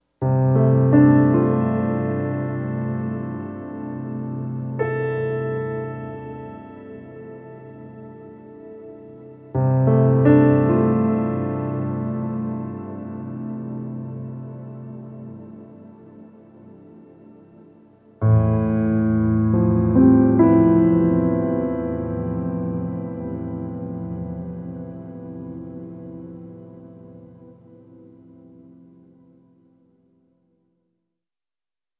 Treated Piano 04.wav